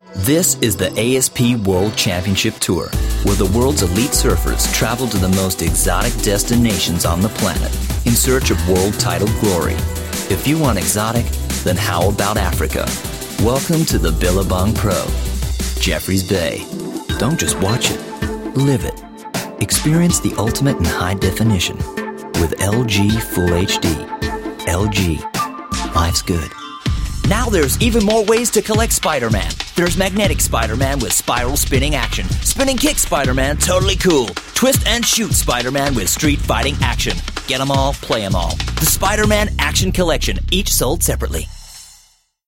US Compilation